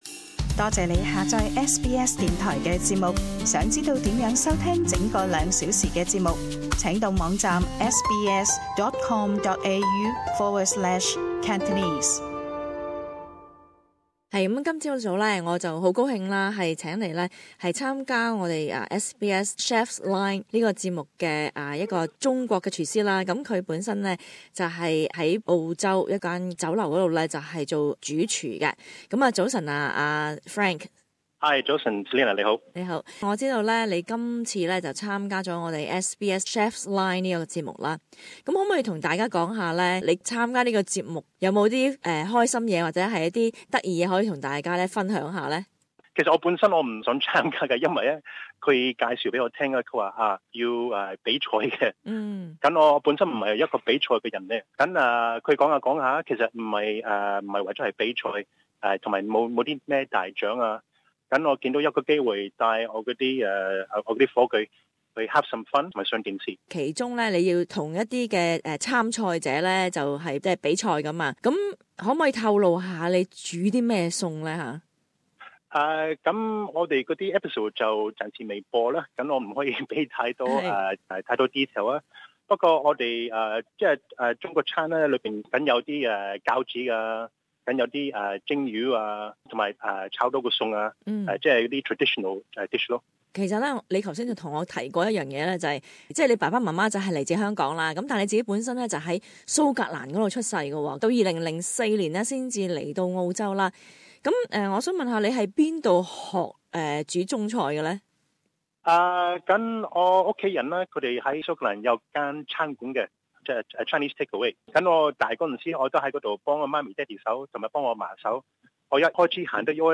【社區訪問】